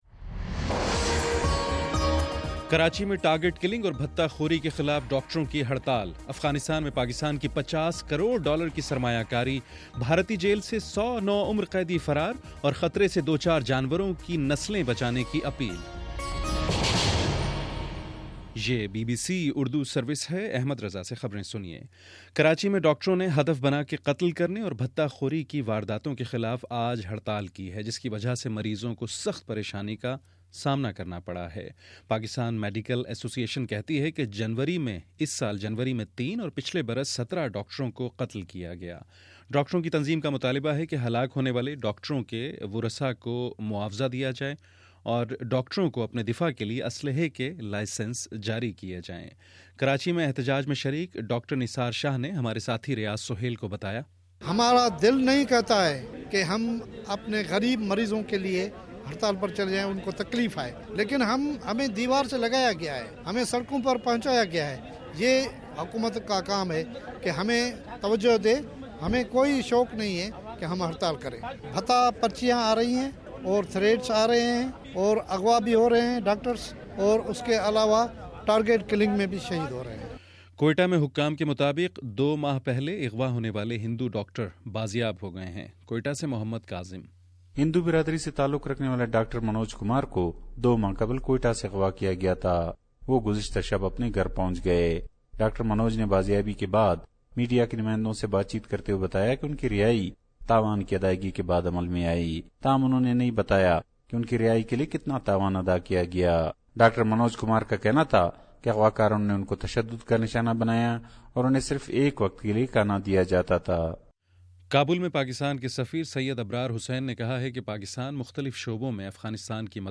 فروری 02: شام چھ بجے کا نیوز بُلیٹن